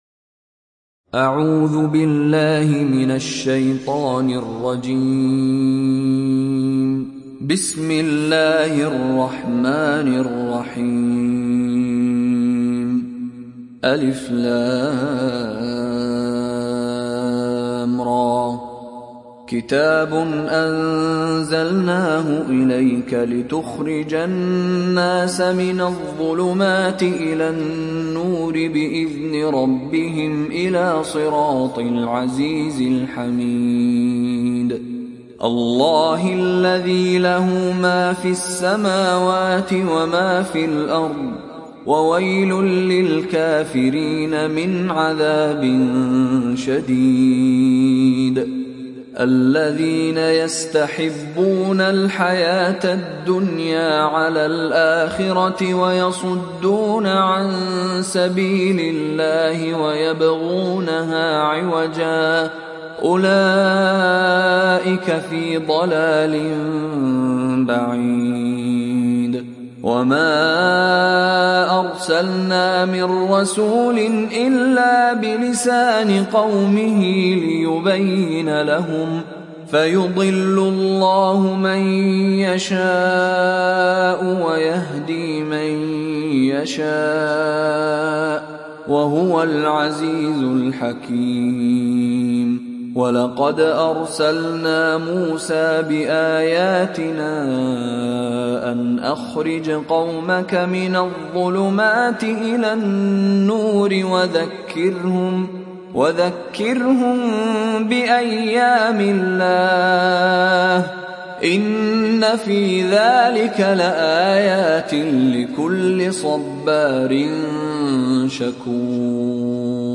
সূরা ইব্রাহীম mp3 ডাউনলোড Mishary Rashid Alafasy (উপন্যাস Hafs)
সূরা ইব্রাহীম ডাউনলোড mp3 Mishary Rashid Alafasy উপন্যাস Hafs থেকে Asim, ডাউনলোড করুন এবং কুরআন শুনুন mp3 সম্পূর্ণ সরাসরি লিঙ্ক